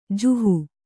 ♪ juhu